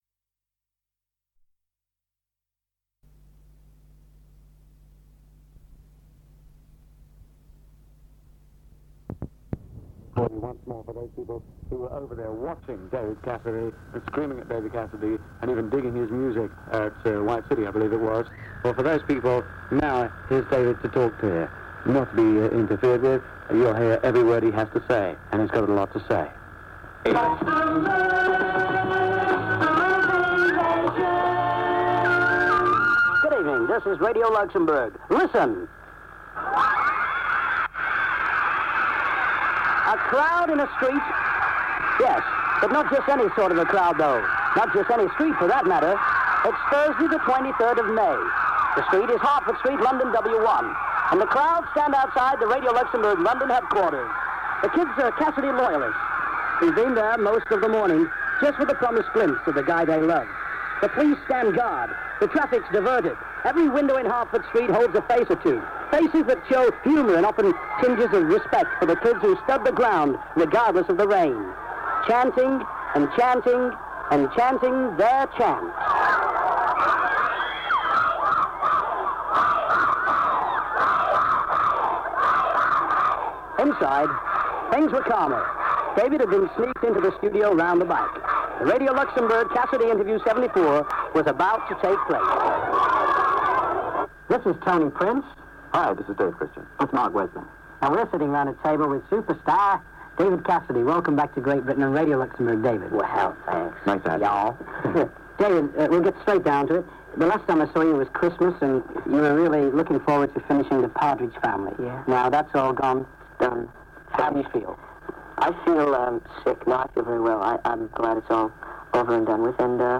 Radio Interview
David was at the studio of Radio Luxembourg - so were many of his fans!